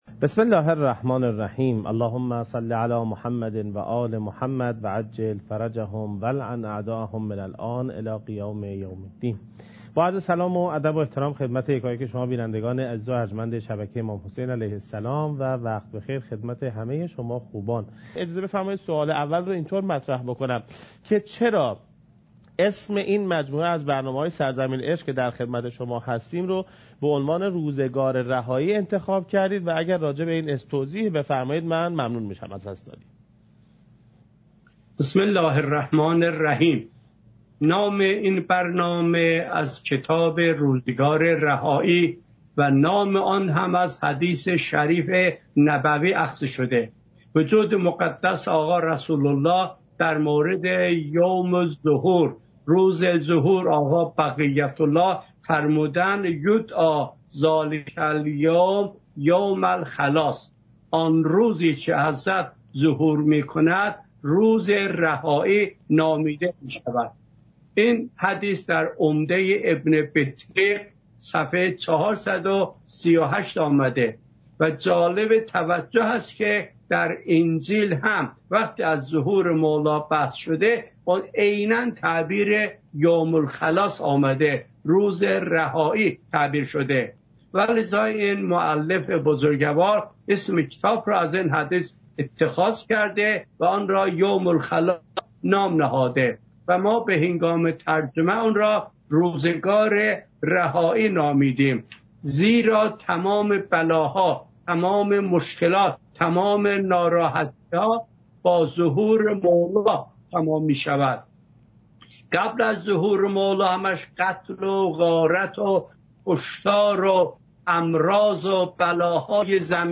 مکان: کربلا